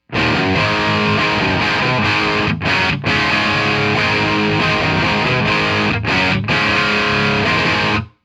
Jet City 1×12 closed back cabinet loaded with a custom voiced Eminence speaker.
All examples recorded using a Jet City JCA20H 20watt head loaded with Groove Tube 12AX7’s and JJ Electronics EL84’s. For the “metal” examples a T Rex Crunchy Frog overdrive pedal and Decimator noise gate are added to the signal chain before the amp.
The guitar used is a 1978 Gibson Les Paul with a Burstbucker Pro pickup in bridge position and TonePros bridge modifications.
All examples were recorded with Audix I5 mics placed directly on the speaker grill at a 45 degree angle and run straight into a Roland Octa-Capture with no signal processing and no processing within SONAR X1 Producer Expanded.
Rock–Jet City 1×12